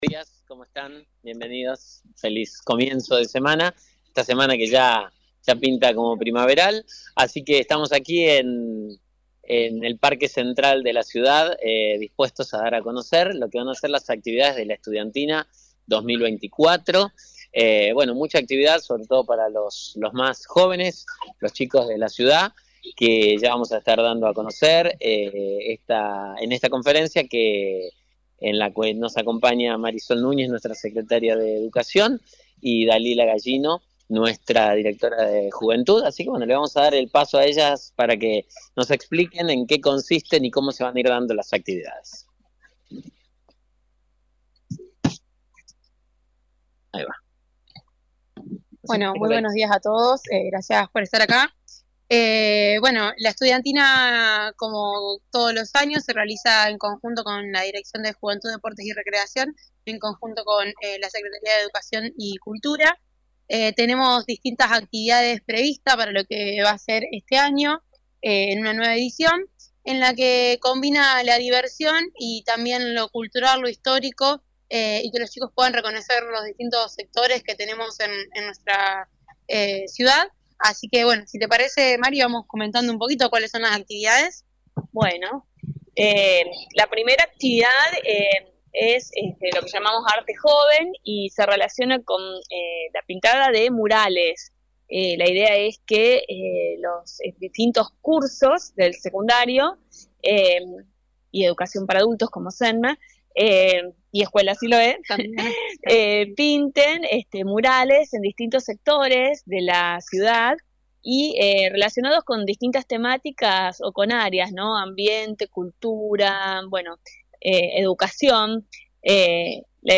De la conferencia, participó el intendente municipal Mauricio Actis, la secretaria de educación Marisol Núñez, y la directora de juventud, deporte y recreación Dalila Gallino.